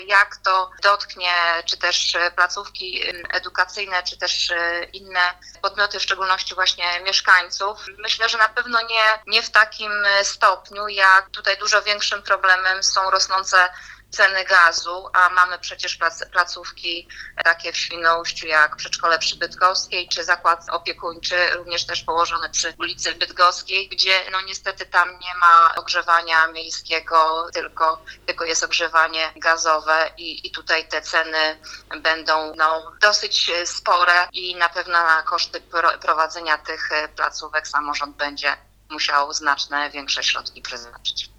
– Mówi Elżbieta Jabłońska, Przewodnicząca Rady Miasta w Świnoujściu.